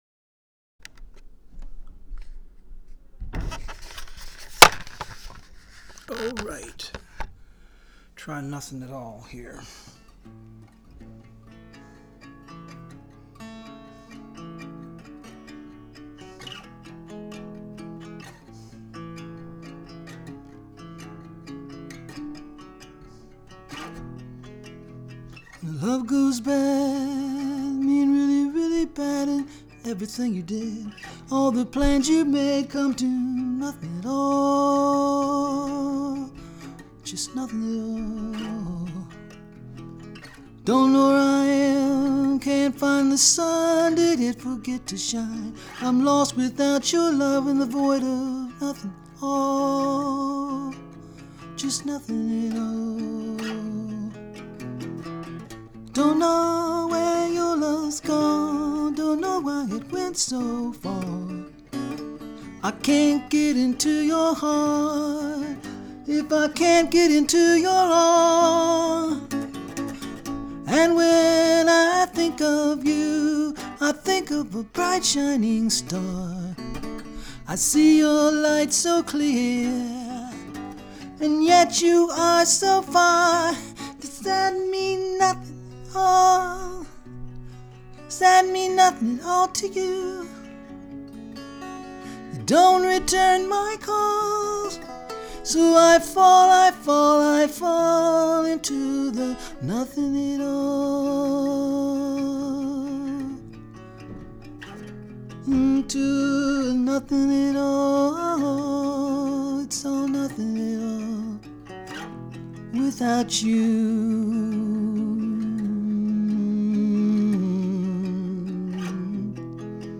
The production is not great, some technical difficulties, but it has some good elements so I'll post it until I can do a total redo and add another track or two.
By the way, one line I really fumbled a word: I jump into my car, not fump into my car.